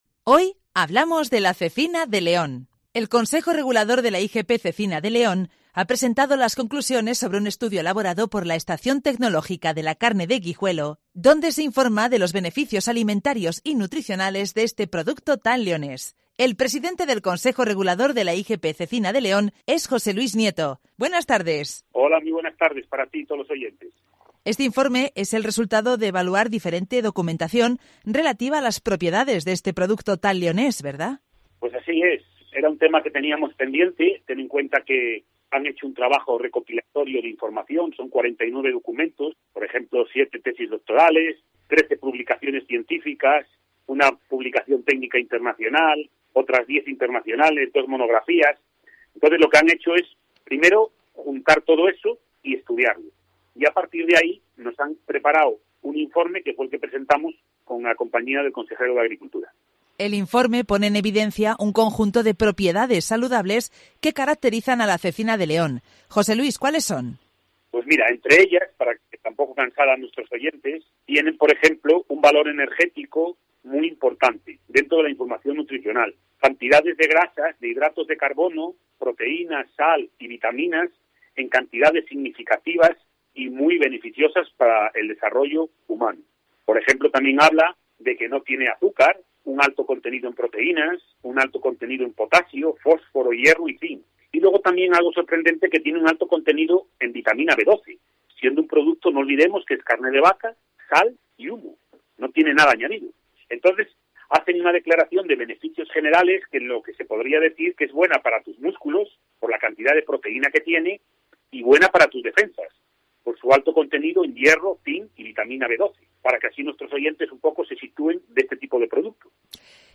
ha explicado cuáles son esas conclusiones en el programa local de Cope León.